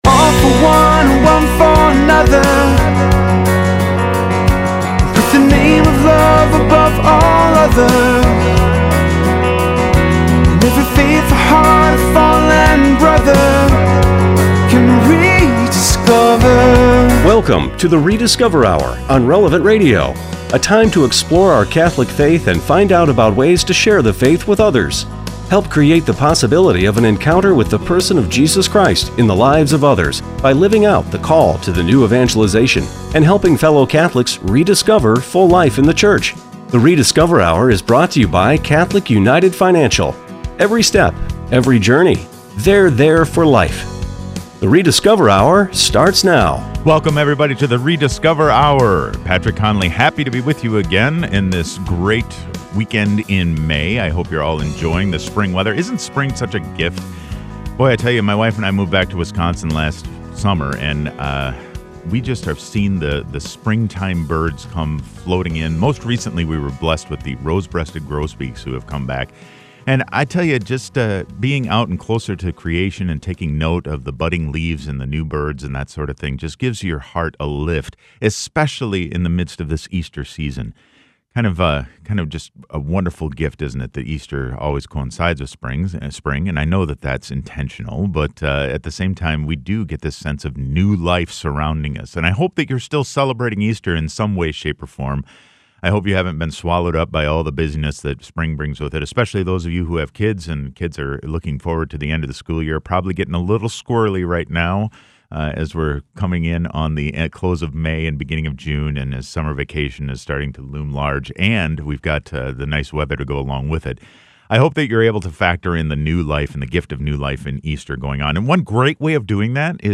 ¡Acompáñenos para escuchar a nuestros futuros sacerdotes recién ordenados!